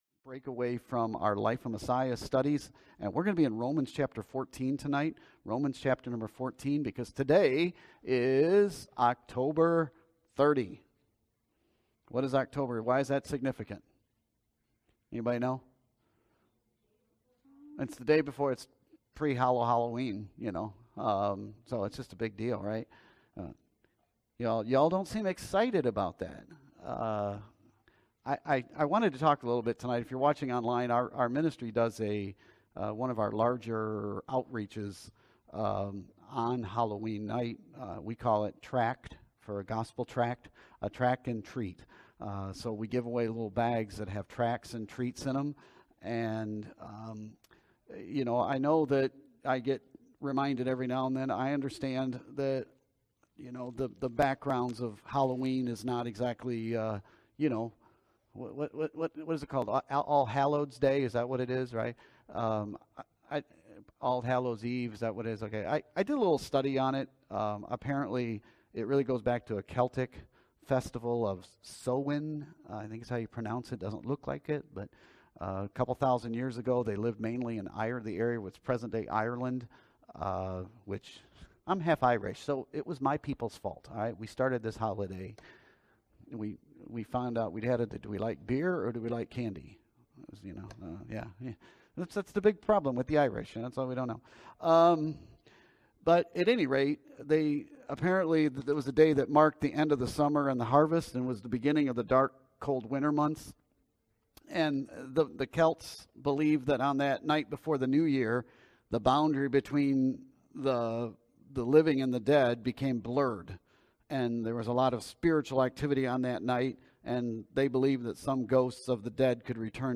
Sermons & Teachings | Open Door Baptist Church